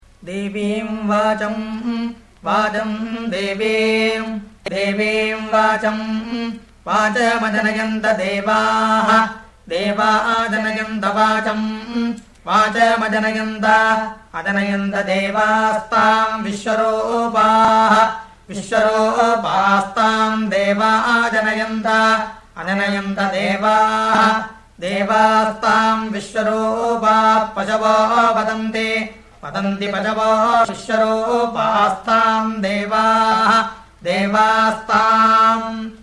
Vikrti Recitation